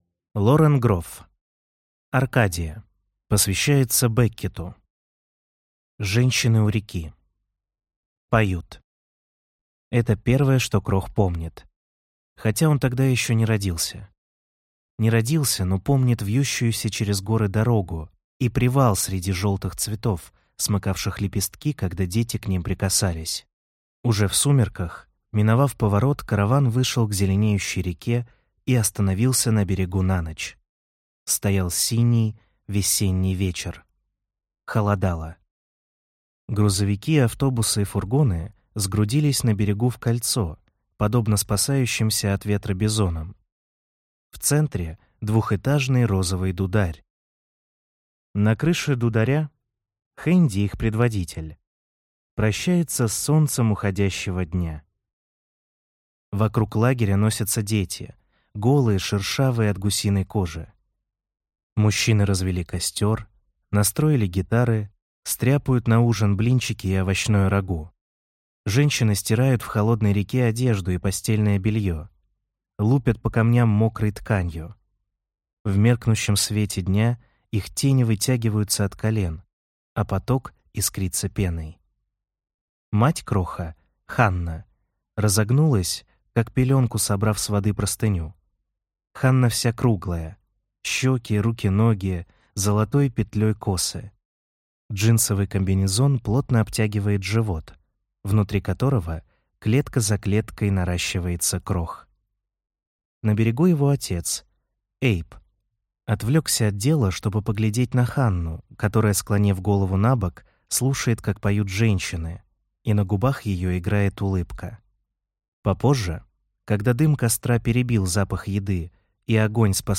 Аудиокнига Аркадия | Библиотека аудиокниг